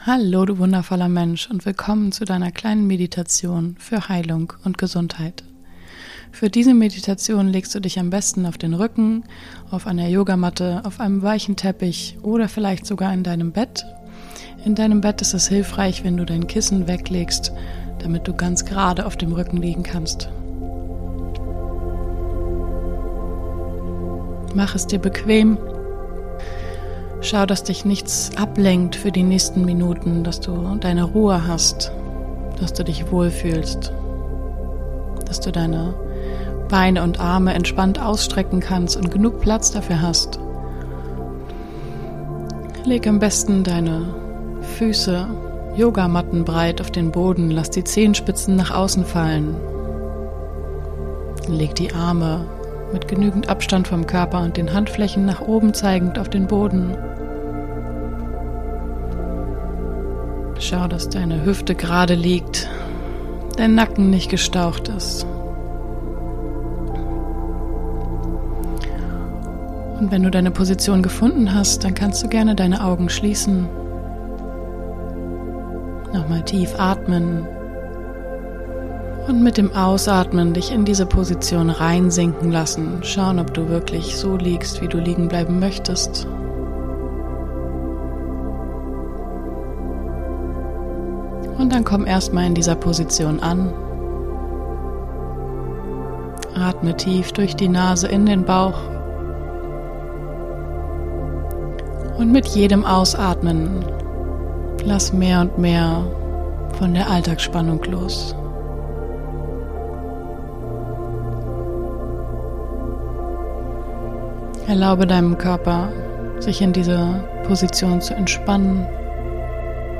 Beschreibung vor 1 Jahr Diese Meditation ist ein Weihnachtsgeschenk, wenn du grade etwas Ruhe, Heilung und Gesundheit gebrauchen kannst.